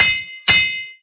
Bell2.ogg